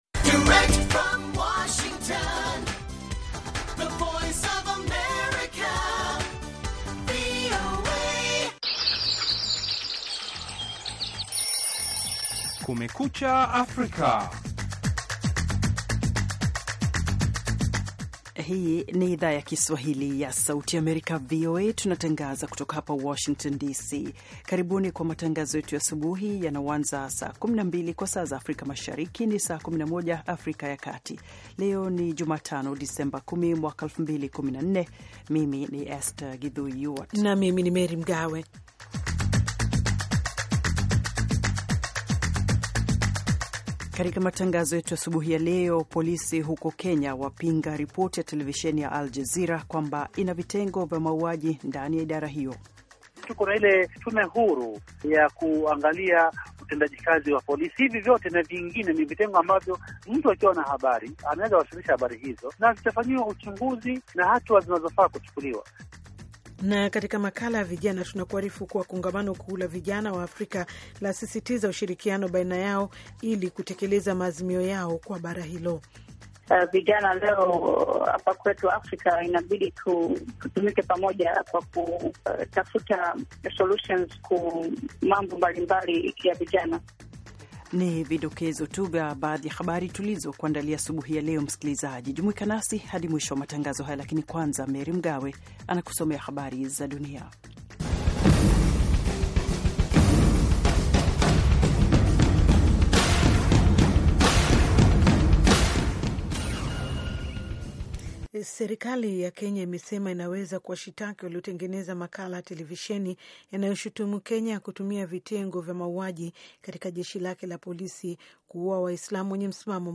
Matangazo ya nusu saa kuhusu habari za mapema asubuhi pamoja na habari za michezo.